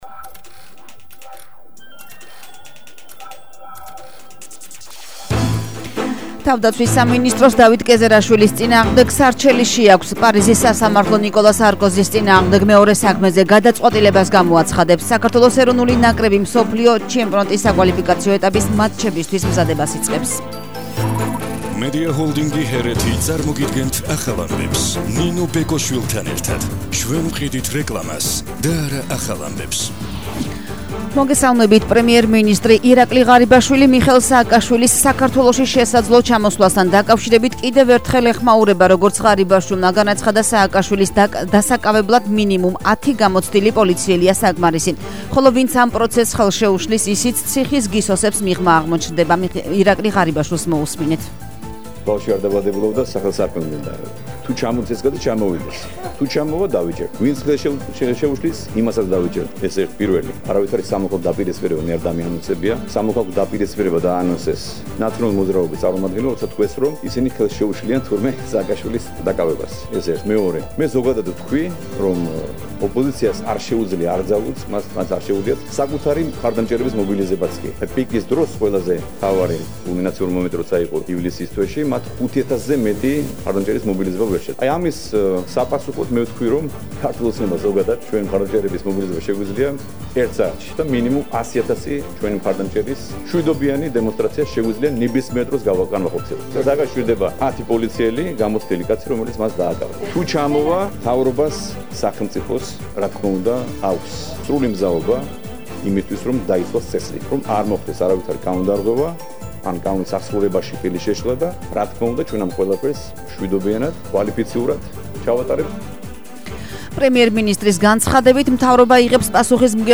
ახალი ამბები 13:00 საათზე –30/09/21